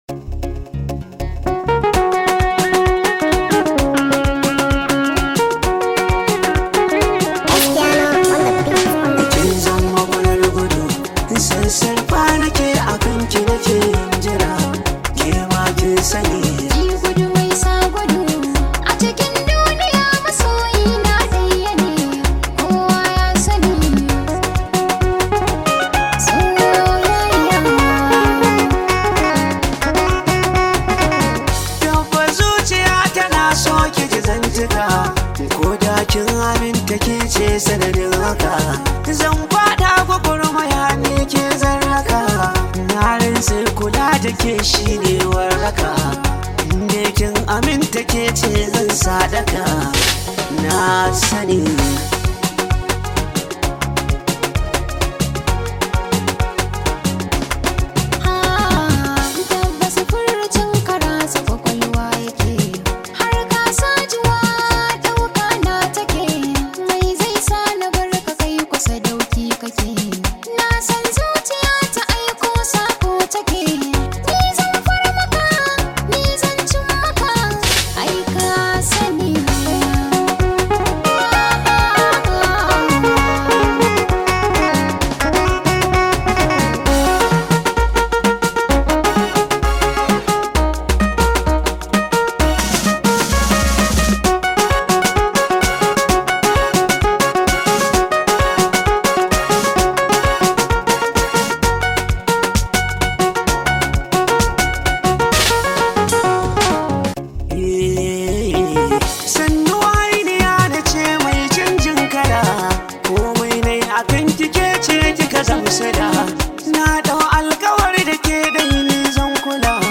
This high vibe hausa song